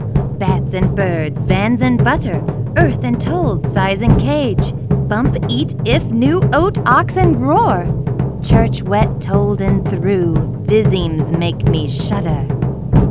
You will notice that you cannot decipher the speech when you play the speech plus noise file; but upon proper implementation of the noise cancellation algorithm you will be able to decipher the speech.
Signal + noise